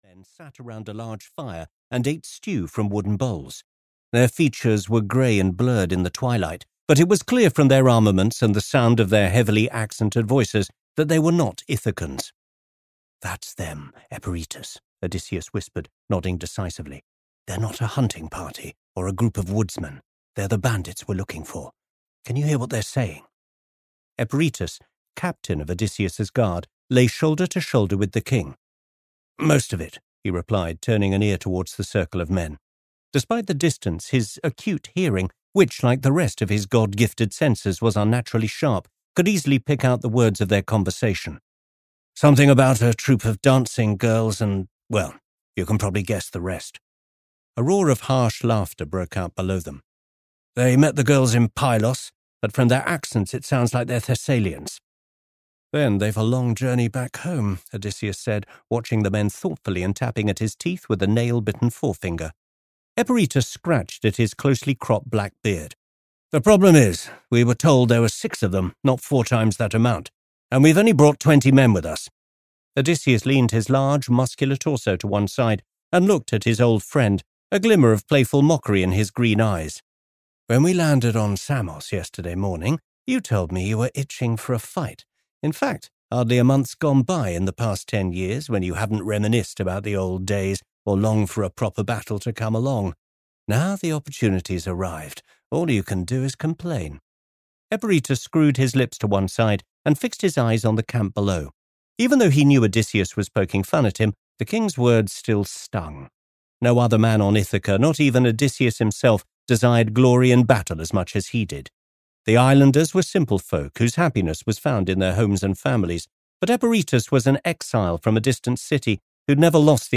Audio knihaThe Gates of Troy (EN)
Ukázka z knihy